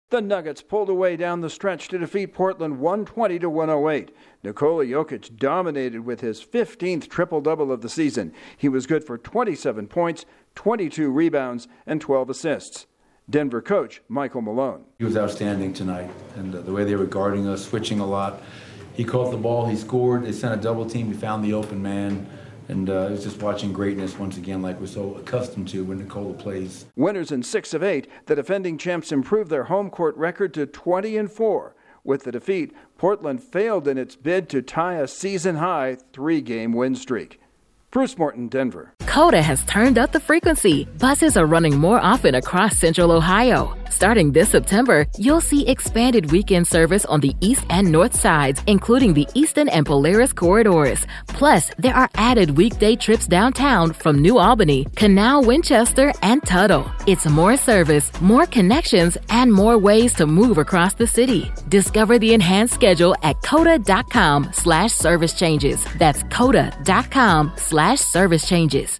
The Nuggets continue their hot stretch by defeating the Trail Blazers. Correspondent